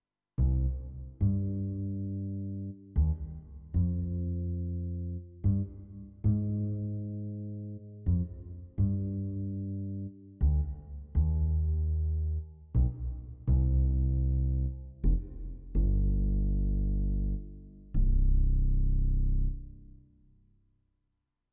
14. I SUONI - GLI STRUMENTI XG - GRUPPO "BASS"
02. VX Uprght Bass
XG-04-02-VXUprghtBass.mp3